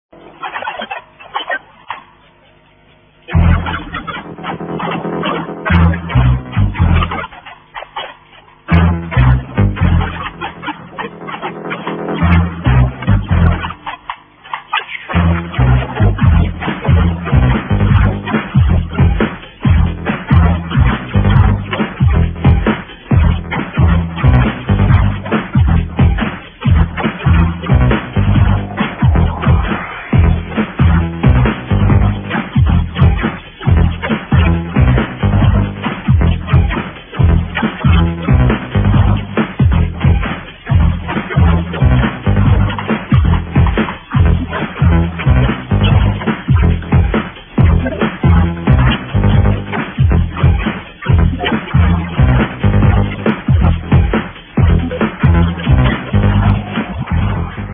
Hello! uknown breaky tune (please help)